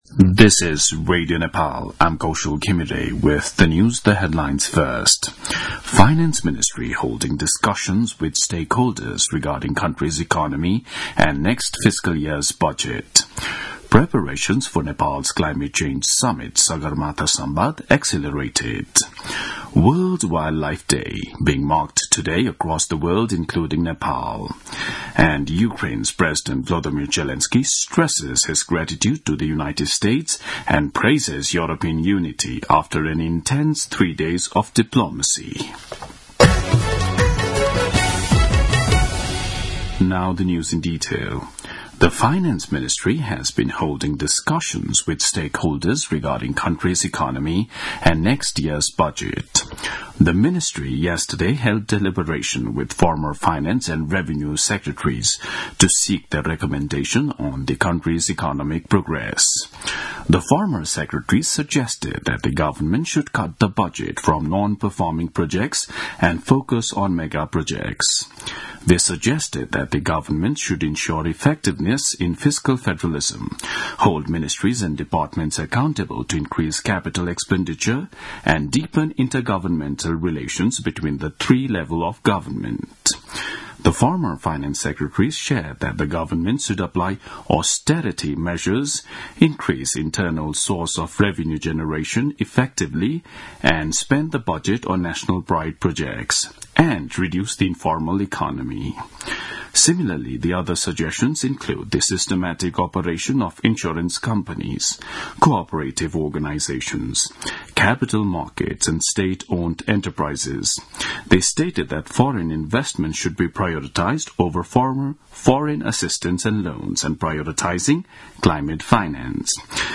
दिउँसो २ बजेको अङ्ग्रेजी समाचार : २० फागुन , २०८१
2-pm-news.mp3